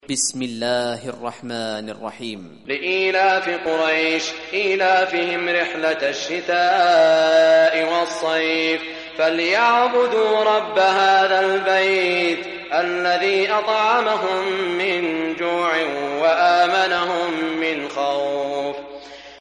Surah Quraish Recitation by Sheikh Saud Suraim
Surah Quraish, listen or play online mp3 tilawat / recitation in the beautiful voice of Sheikh Shuraim.